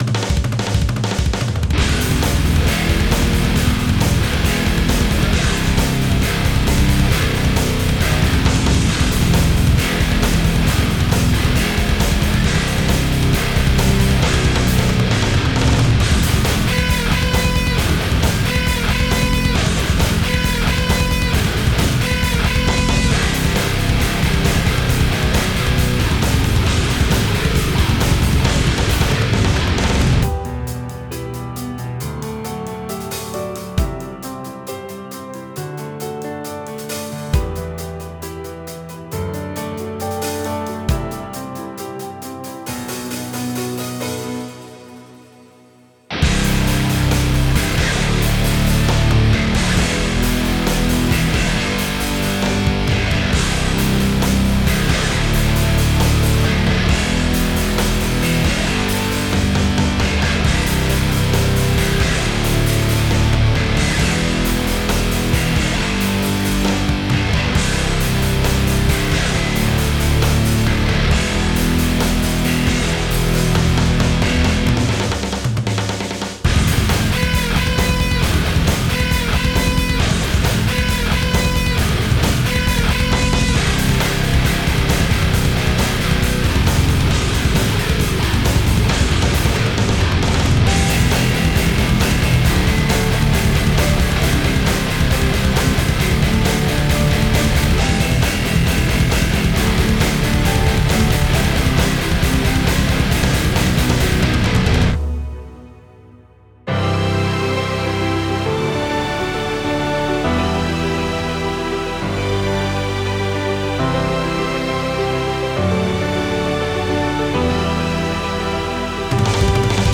8弦ギターを使いました！